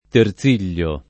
terziglio [ ter Z& l’l’o ]